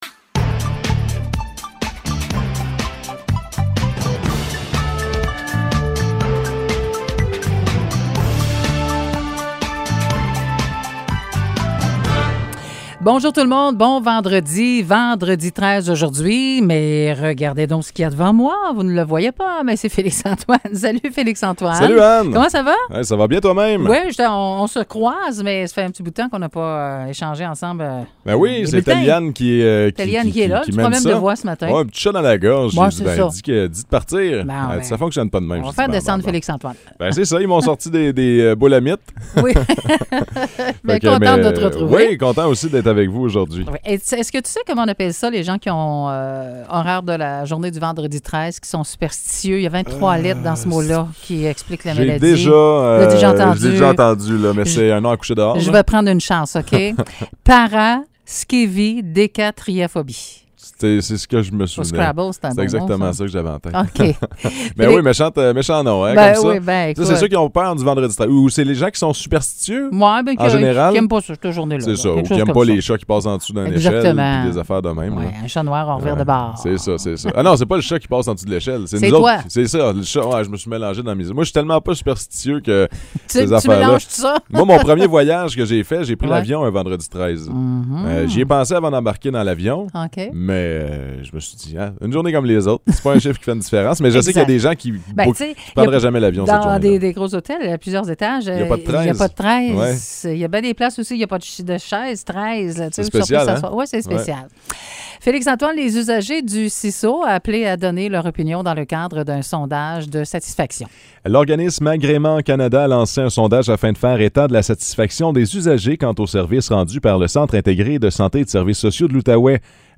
Nouvelles locales - 13 mai 2022 - 9 h